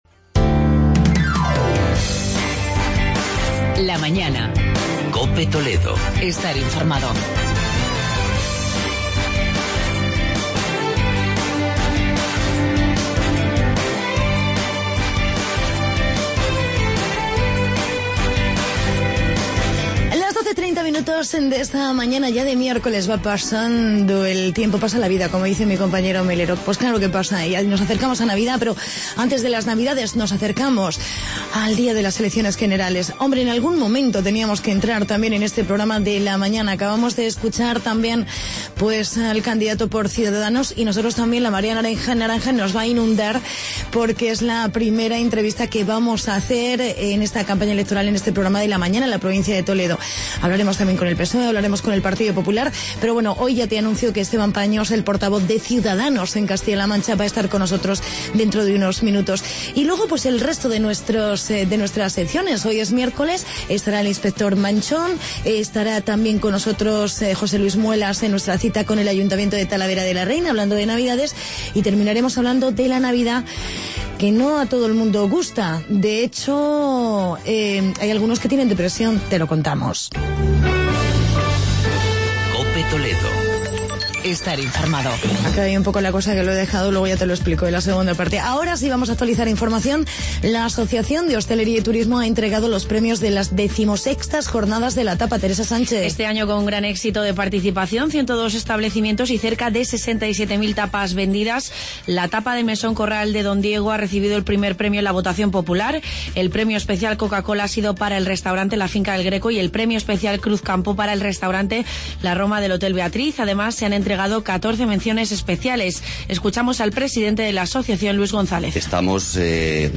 Entrevista a Esteban Paños, portavoz de CIUDADANOS en CLM.